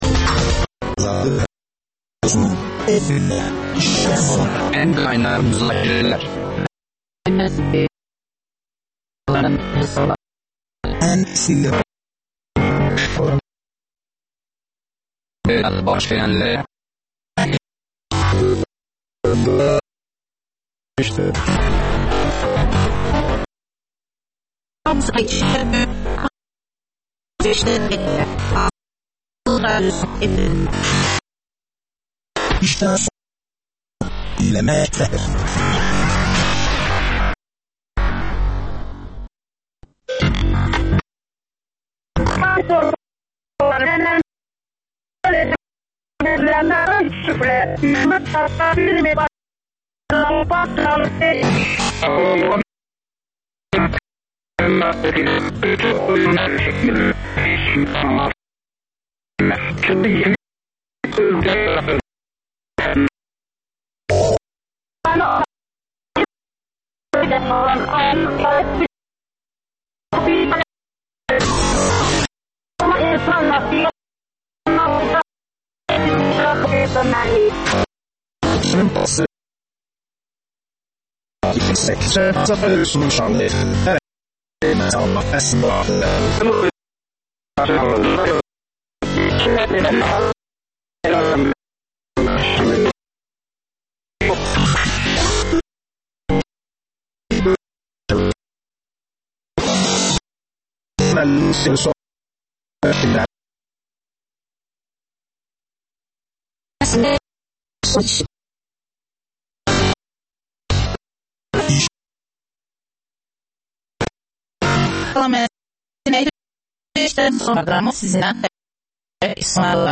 İqtisadçı ekspertlər